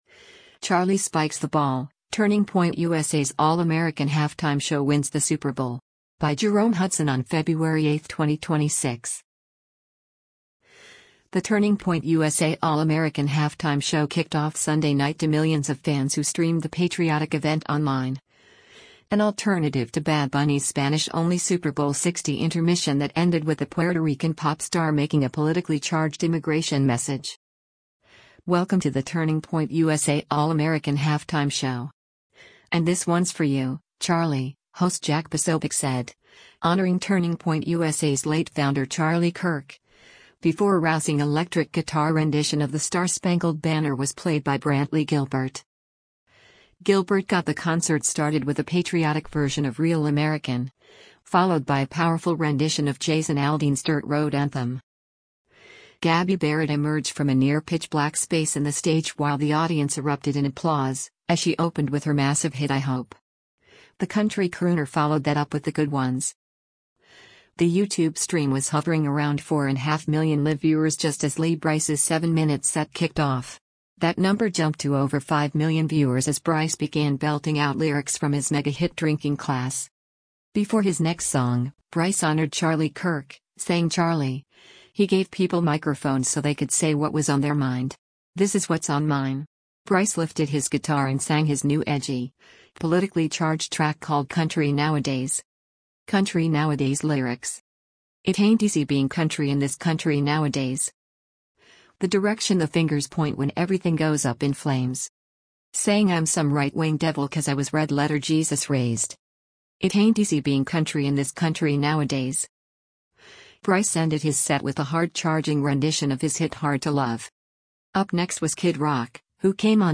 rousing electric guitar rendition
while the audience erupted in applause